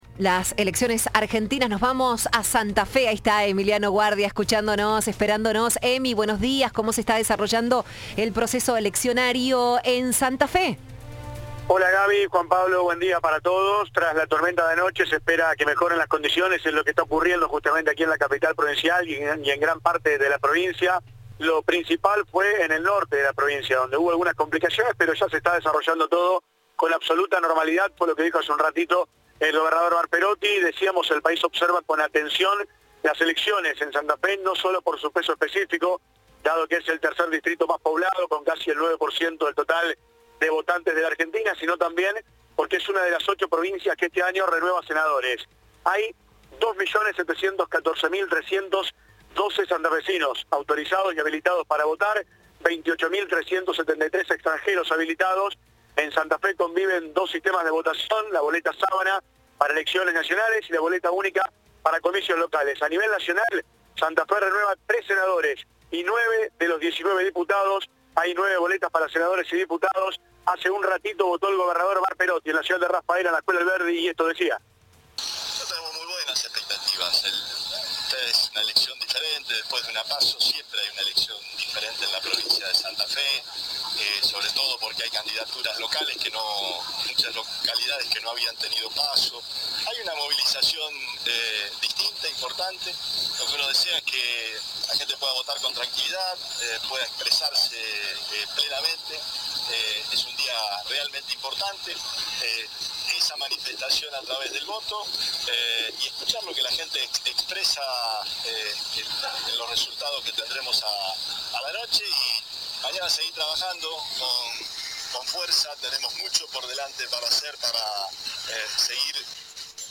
El gobernador santafesino emitió su voto antes del mediodía de este domingo. En diálogo con la prensa, aseguró que "es un día realmente importante".